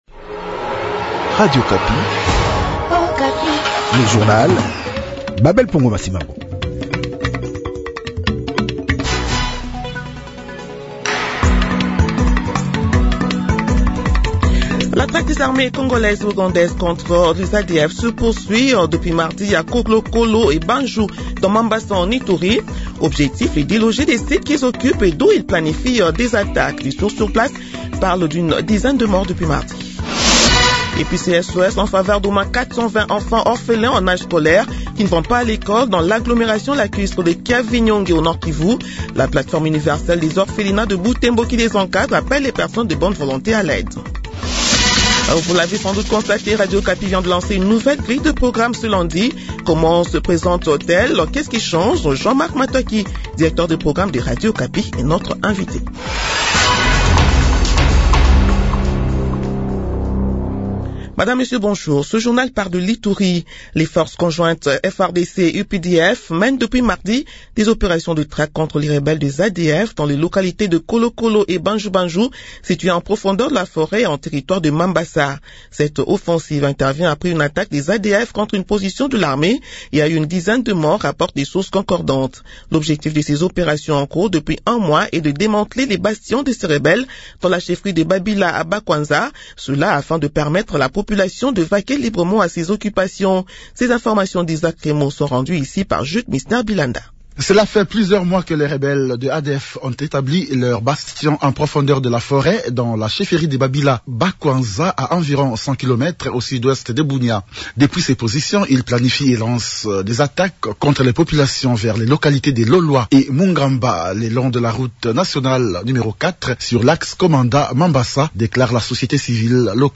Journal 8 h de ce lundi 12 janvier 2026